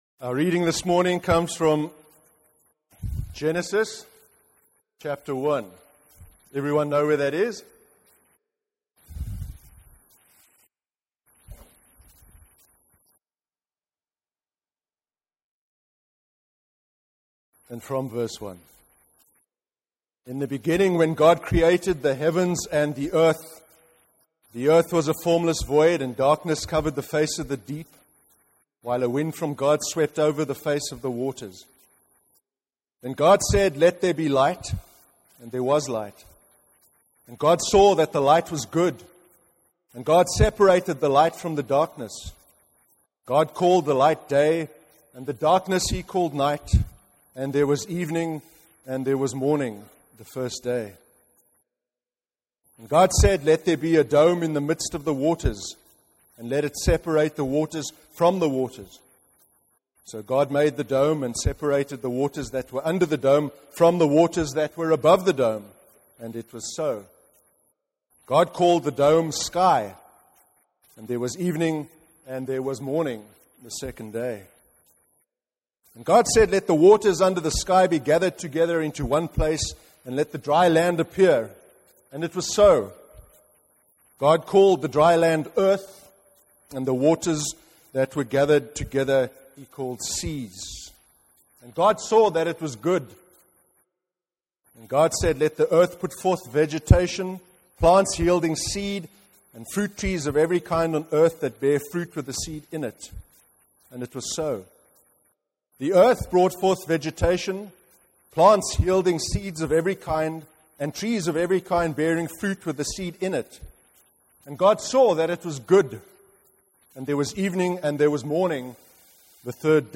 30/11/2014 sermon: Happiness Part 2 (Genesis 1) – NEWHAVEN CHURCH